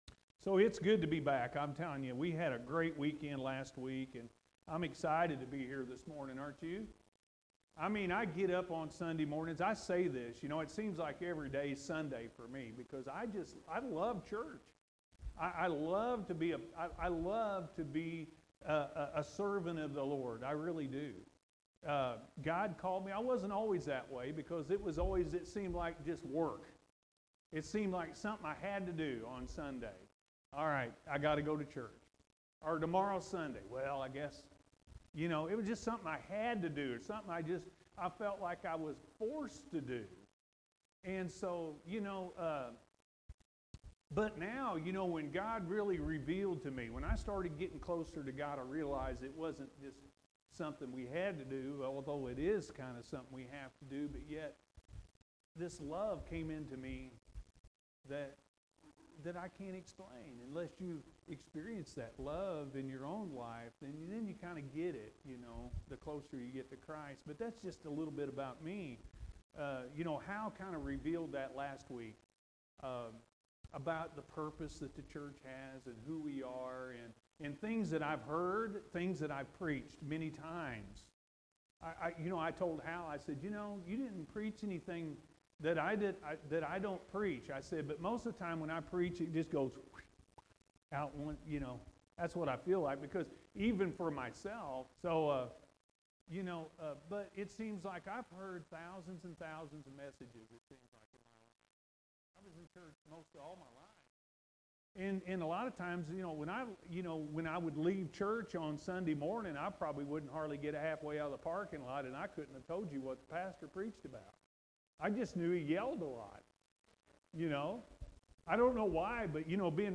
Stand Firm In Our Faith-A.M. Service